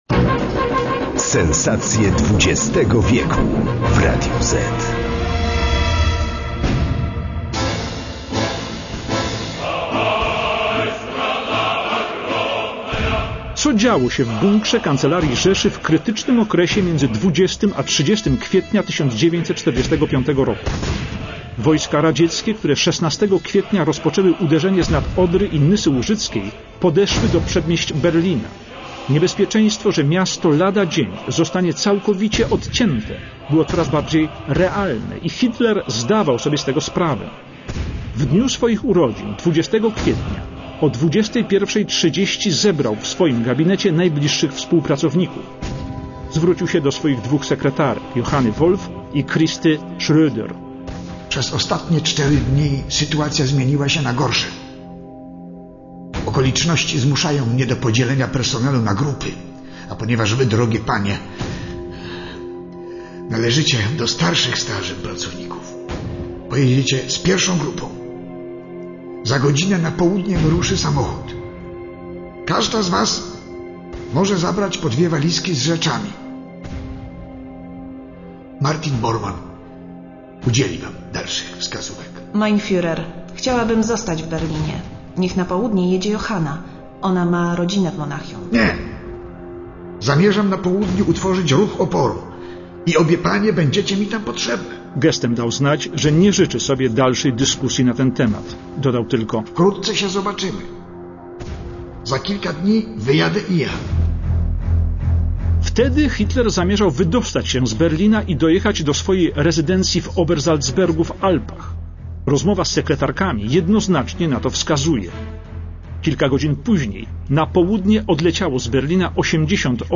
Adolf Hitler - Jan Peszek
Karl Weidling dowódca obrony Berlina - Witold Pyrkosz
Heinz Linge kamerdyner Hitlera - Marek Perepeczko
Pilot fuhrera Hans Baur - Krzysztof Globisz
żołnierz radziecki - Jerzy Bończak